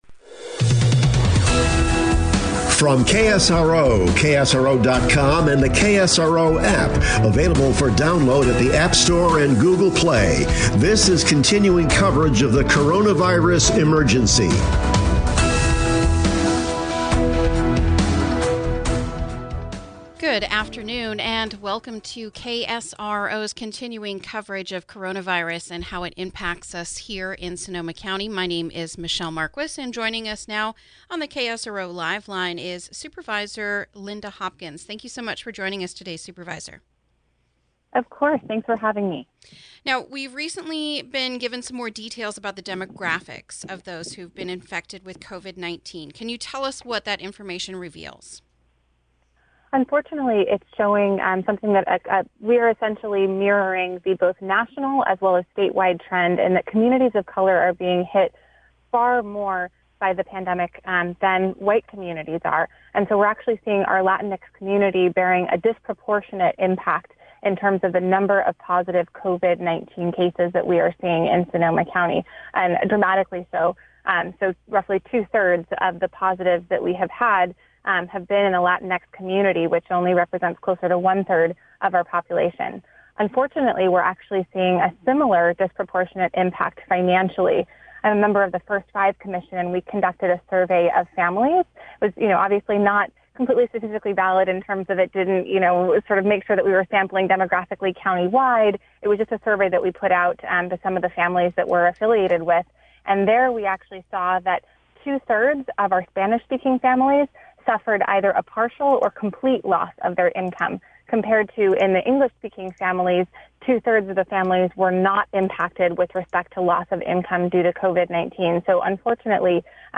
INTERVIEW: Supervisor Lynda Hopkins on disparity in infection rate and financial impact